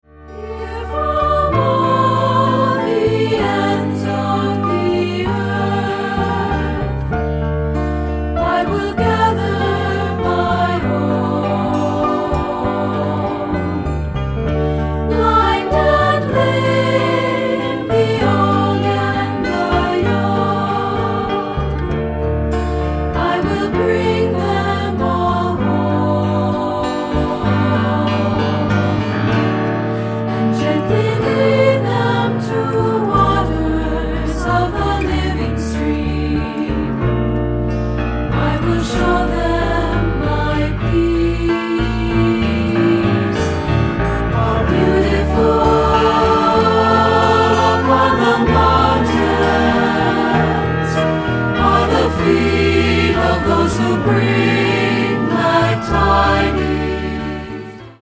Accompaniment:      Keyboard, C Instrument I;C Instrument II
Music Category:      Choral